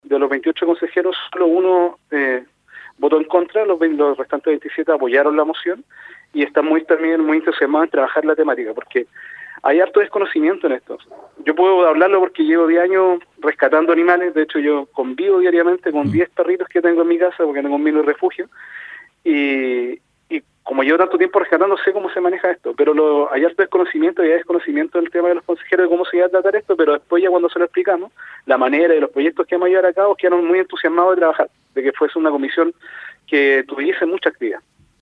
En entrevista con Radio UdeC, destacó el carácter pionero a nivel nacional que tendrá esta iniciativa.